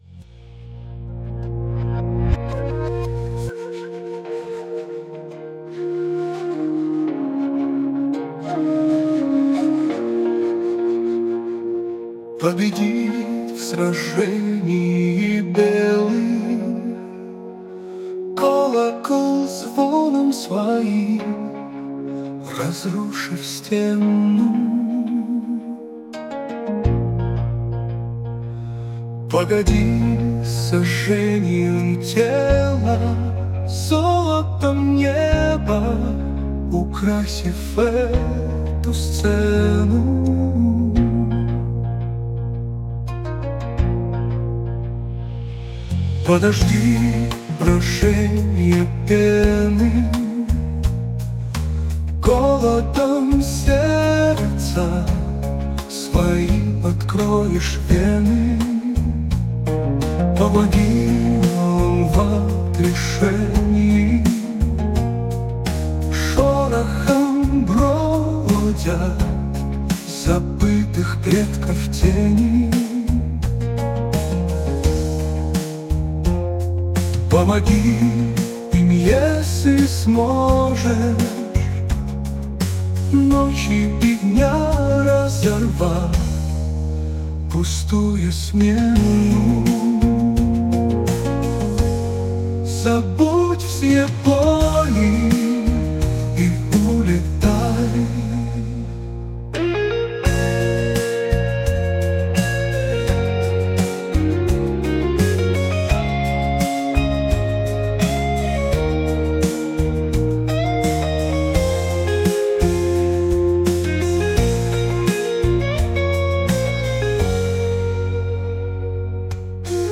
• Жанр: Фолк